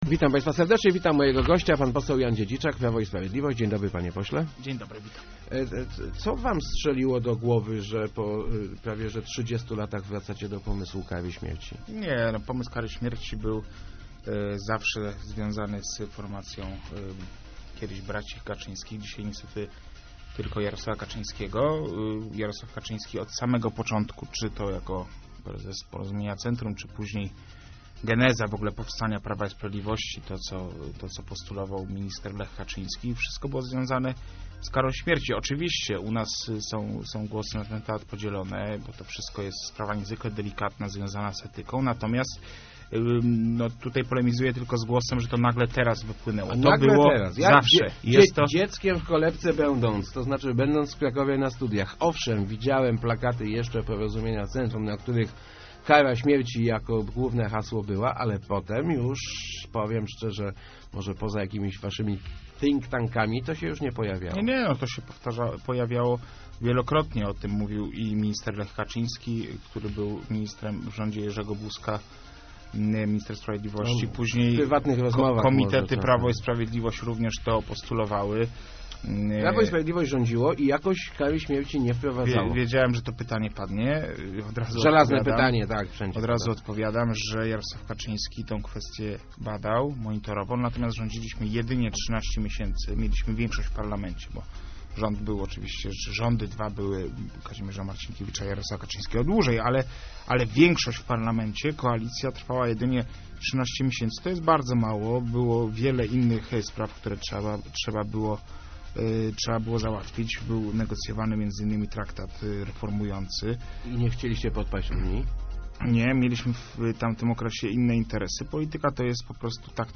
Przywrócenie kary śmierci to nie jest nowość w naszym programie - zapewniał w Rozmowach Elki poseł PiS Jan Dziedziczak. Zaznaczył, że sam nie ma wyrobionego zdania na ten temat.